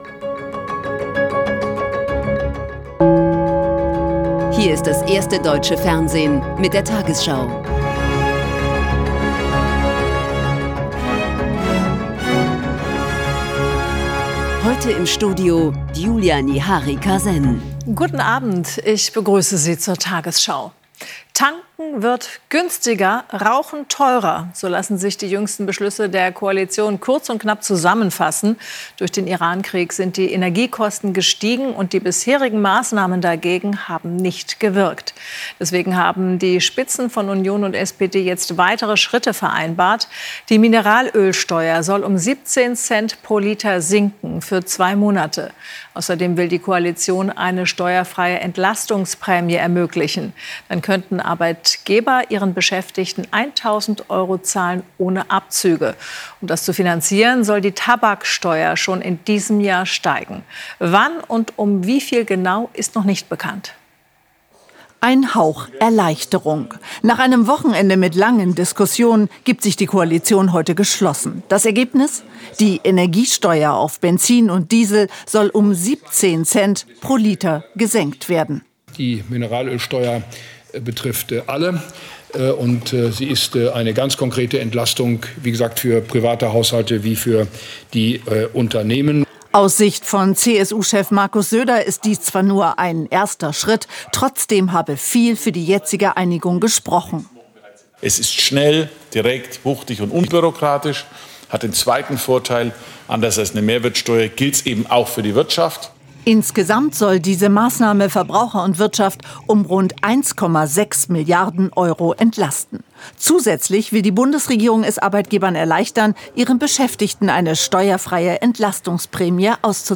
tagesschau 20:00 Uhr, 13.04.2026 ~ tagesschau: Die 20 Uhr Nachrichten (Audio) Podcast